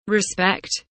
respect kelimesinin anlamı, resimli anlatımı ve sesli okunuşu